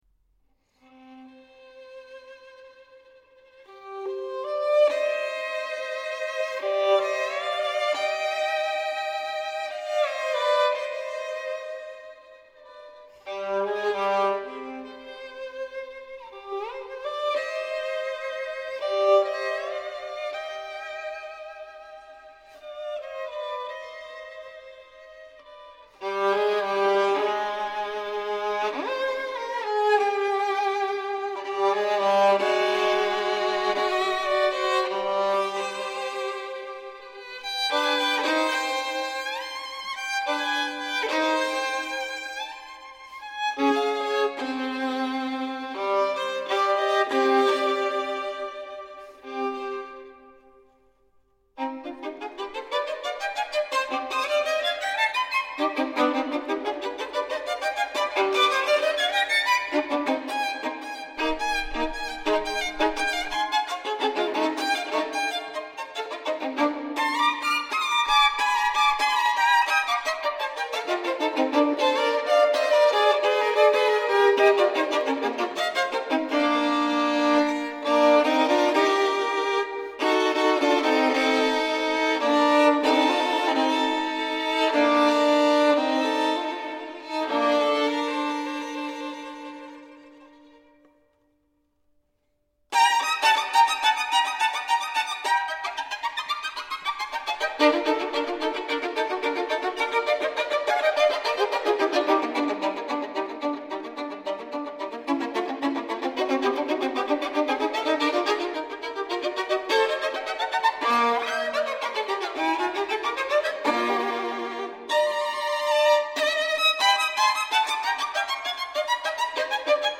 Il suo virtuosismo tende a sottolineare altri gesti linguistici: le asperità armoniche, l’impevedibilità del discorso, la violenza dell’invenzione.
Per chi fosse interessato a un confronto, riporto qui il bellissimo e misterioso inizio del Capriccio n. 4 in Do minore (Maestoso), prima nell’interpretazione di Salvatore Accardo (incisione del 1978) e poi in quella di Zehetmair. La differenza, in termini sia di velocità sia di spirtito, è abbastanza evidente.